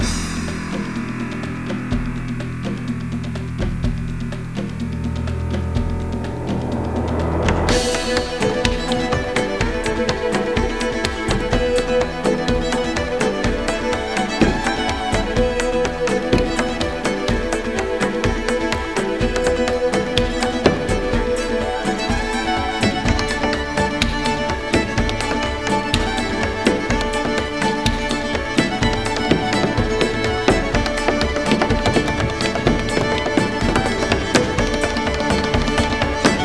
The music from the show is an Irish delight.